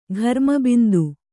♪ gharma bindu